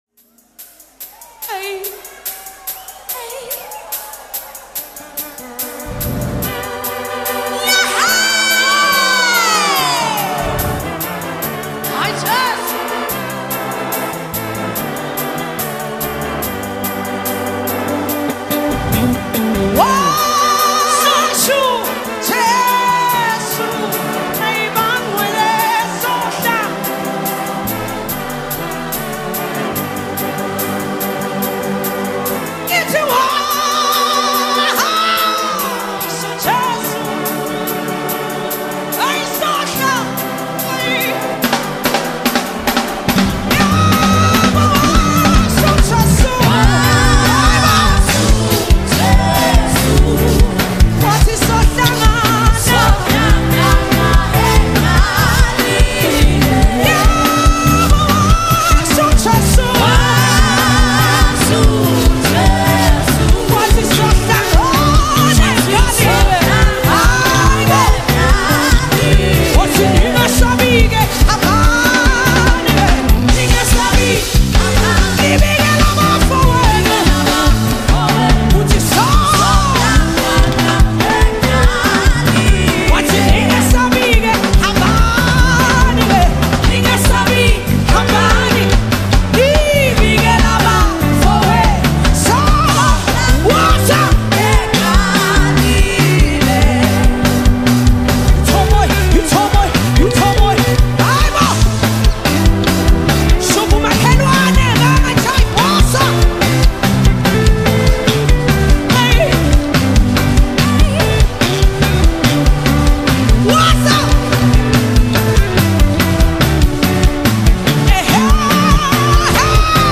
South African gospel
Don’t miss this electrifying live gospel performance.